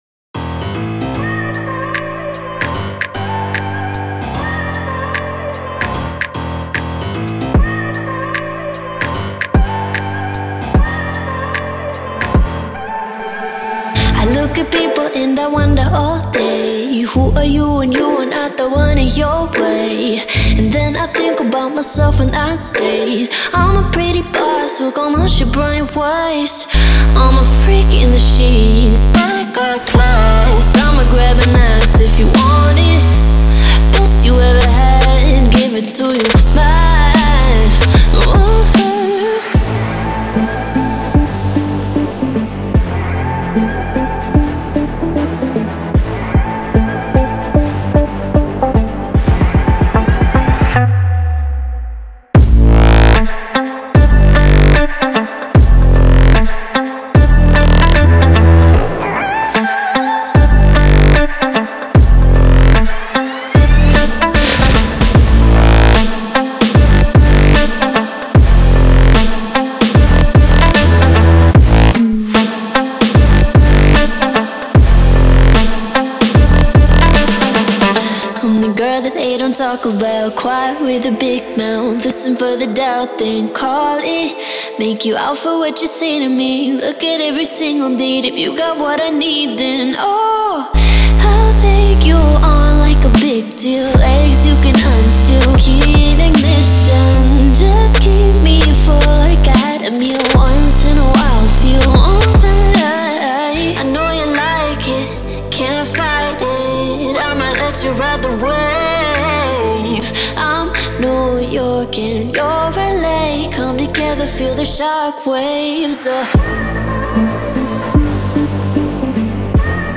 Electronic, Sexy, Epic, Happy, Laid Back